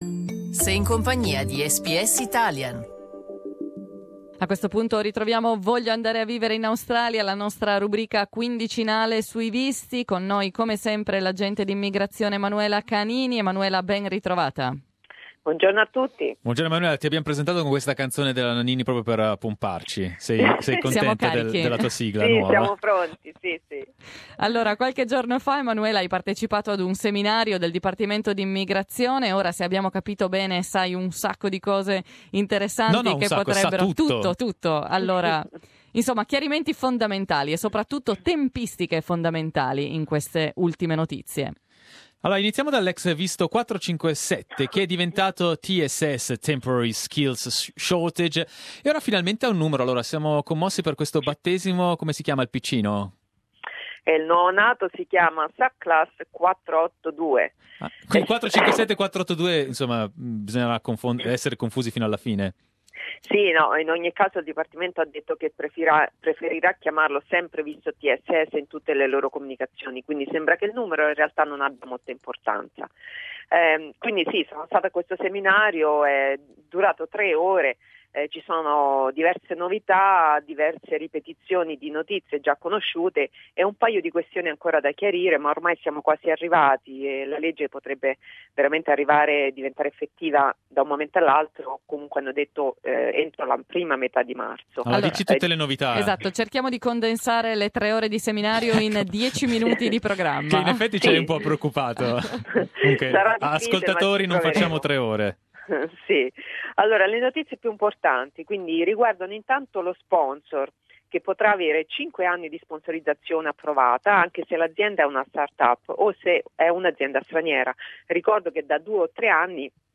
Source: SBS SBS Italian